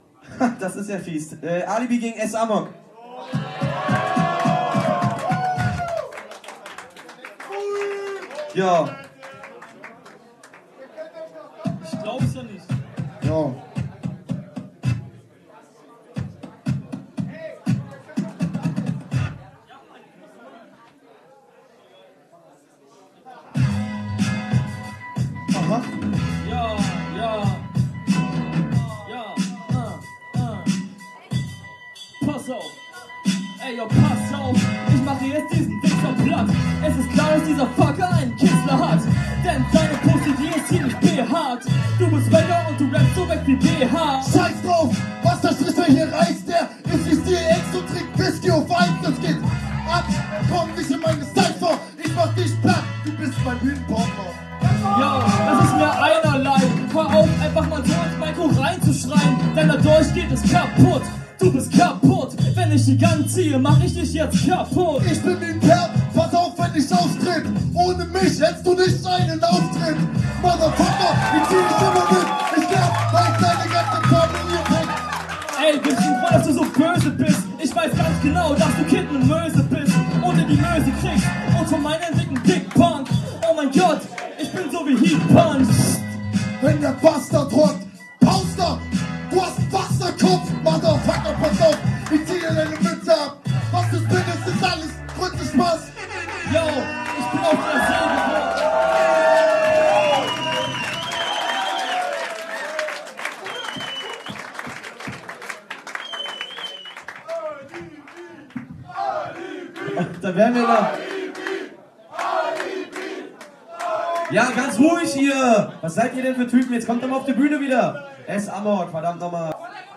an den Turntables